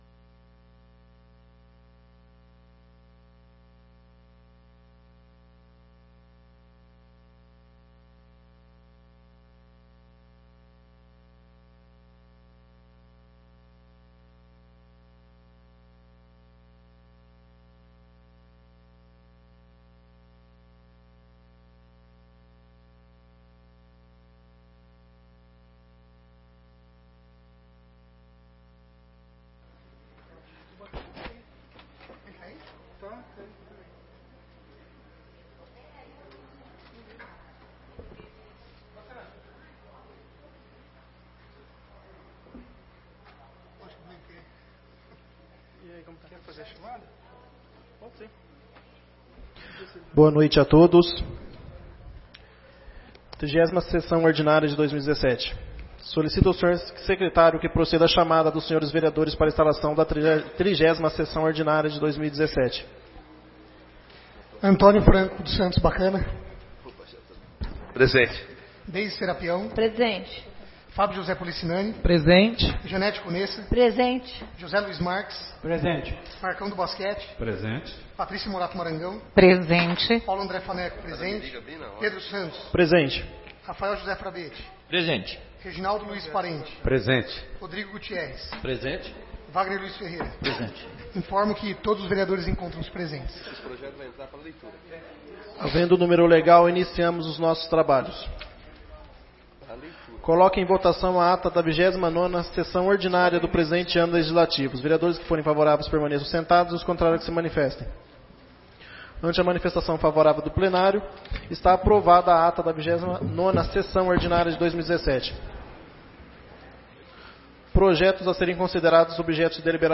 30ª Sessão Ordinária de 2017